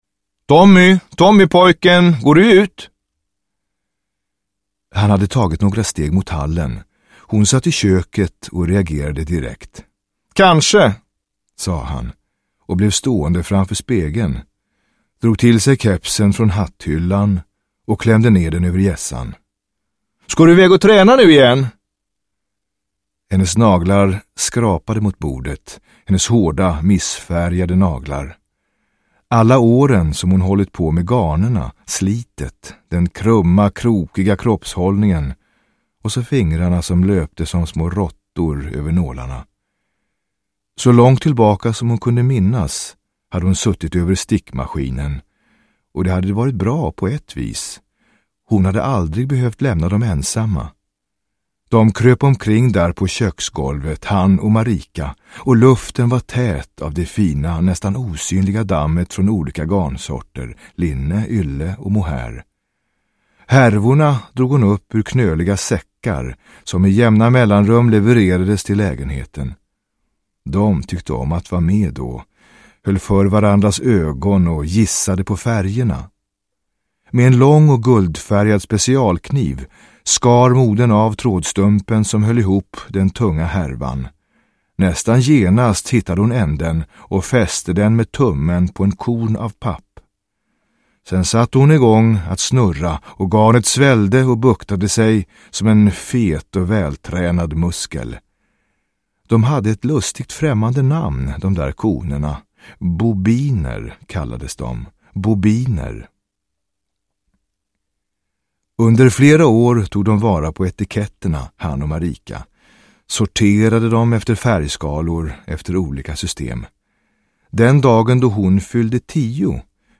Älskade syster – Ljudbok – Laddas ner
En StorySide novell på 38 minuter.